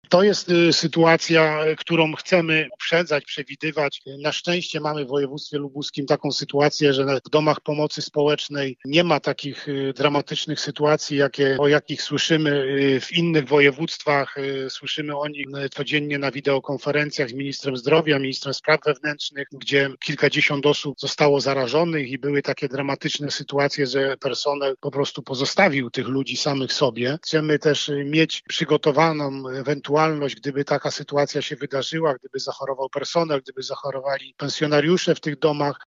– Obserwujemy, co dzieje się w innych regionach w kraju, gdzie dramatycznie brakuje personelu, u nas sytuacja jest stabilna, ale chcemy być przygotowani na taką ewentualność – mówi wojewoda Władysław Dajczak: